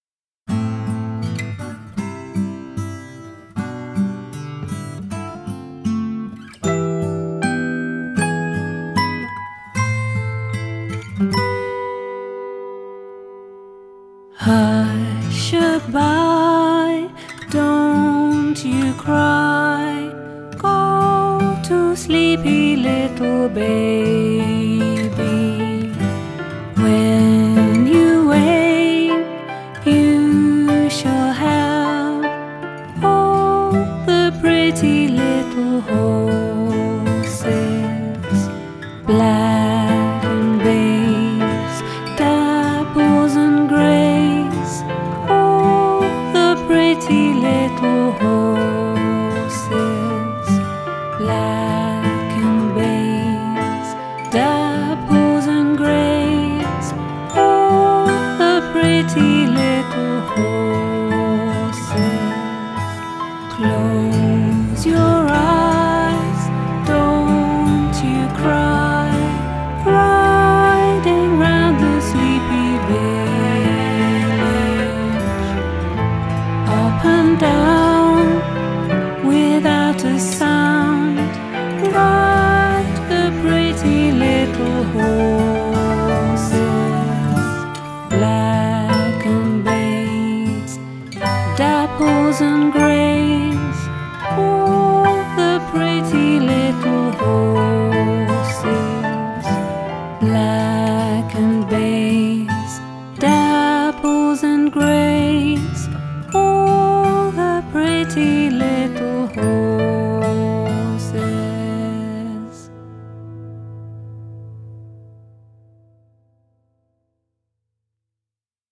LullabyFolk